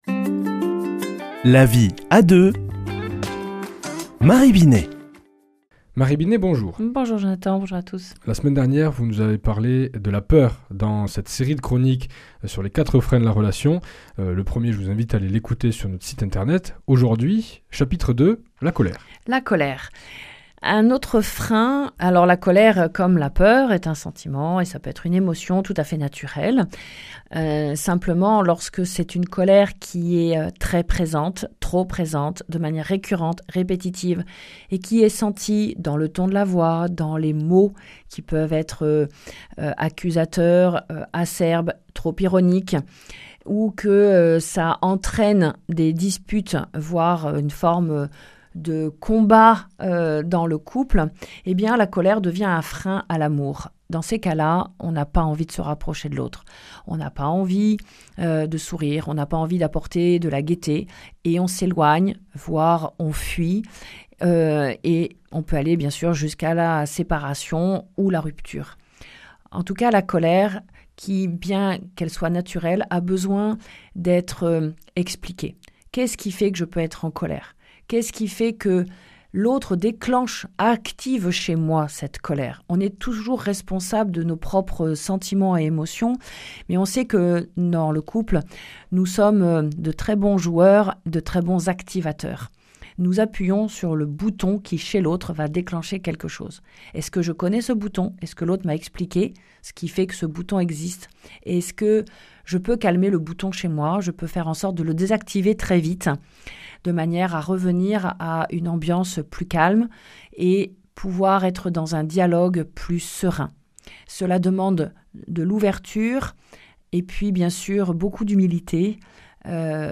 mardi 28 octobre 2025 Chronique La vie à deux Durée 4 min